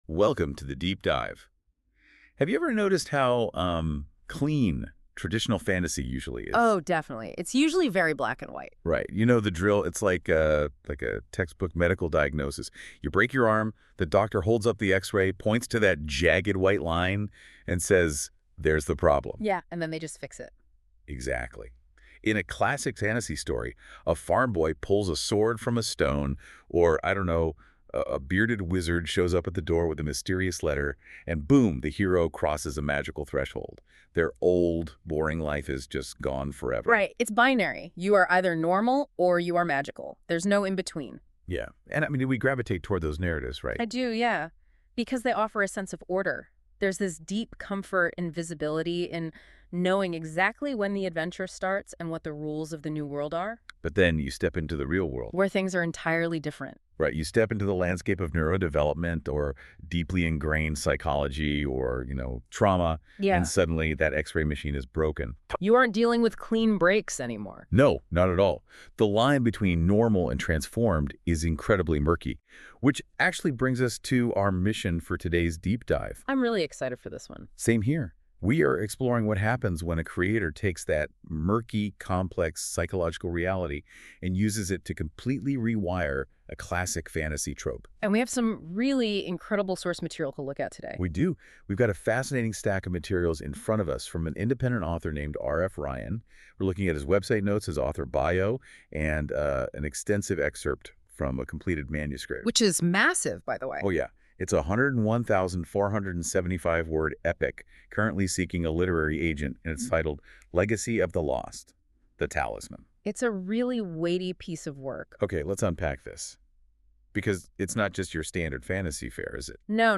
Listen to the Deep Dive Podcast Review of the unpublished Legacy of the Lost: The Talisman sample chapters.